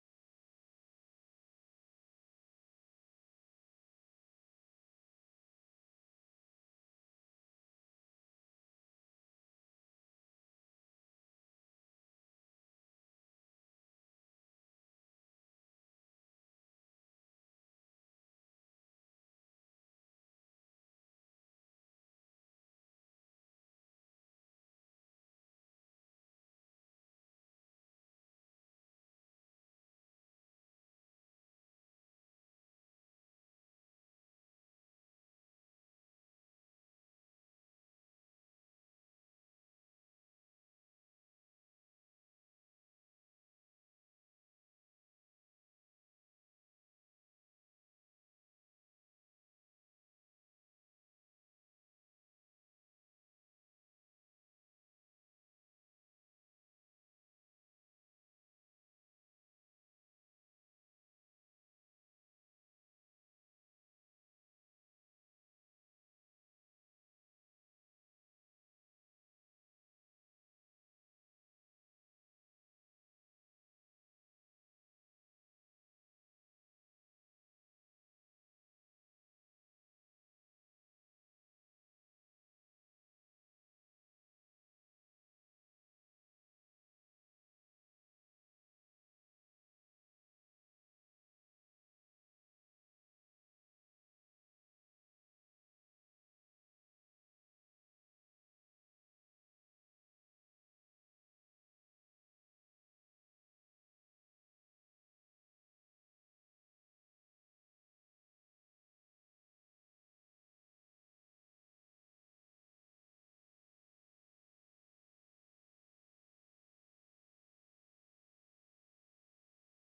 Информация Закрыть Загружено 31.07.2021 11:07 Размер 33.2 MB Просмотров 203 Последний 16.11.2024 16:59 Время 2ч.24мин.58сек. Кодек mp3 Битрейт 32 кбит/сек Частота дискретизации 44.1 КГц MD5 Cоздание трека в стиле Drum&Bass part 2